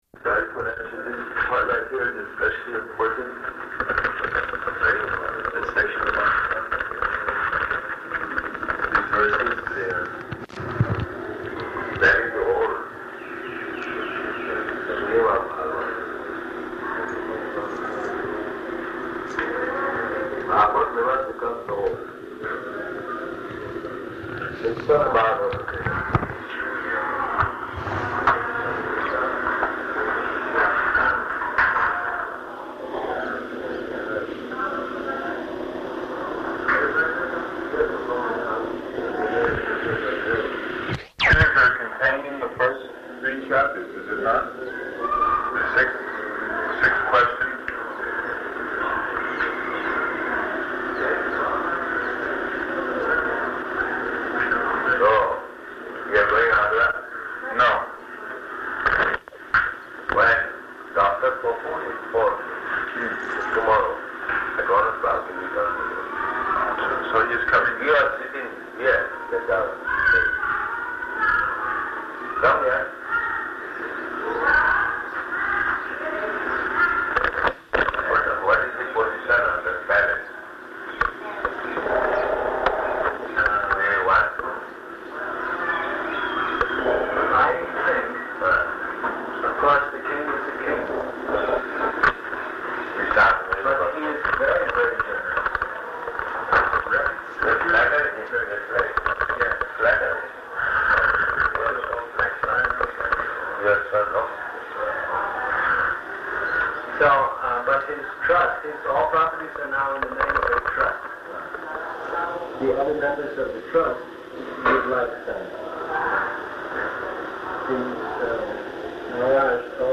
Room Conversation
-- Type: Conversation Dated: November 7th 1972 Location: Vṛndāvana Audio file
[Poor audio]